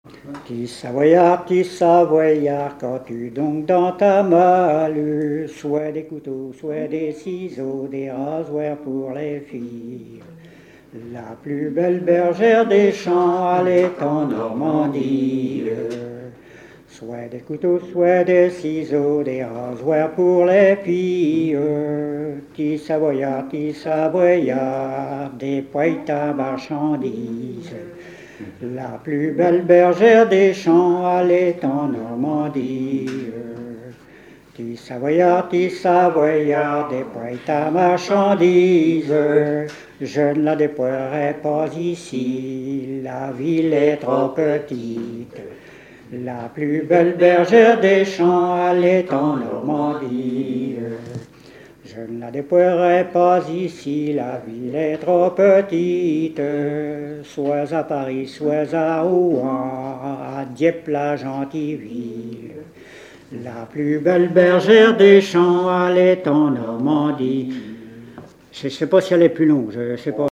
Chansons traditionnelles et témoignages
Pièce musicale inédite